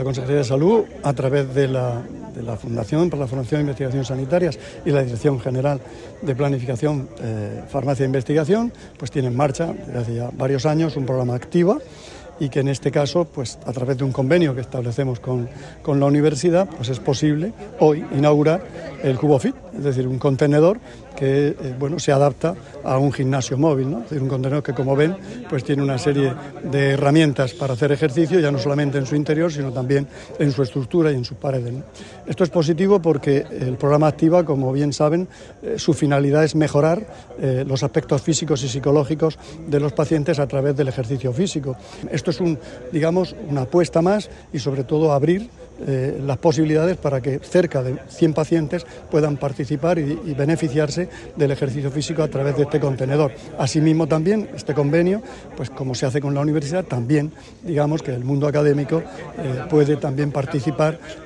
Sonido/ Declaraciones del consejero de Salud, Juan José Pedreño, sobre el 'cubo fit' y el programa Activa.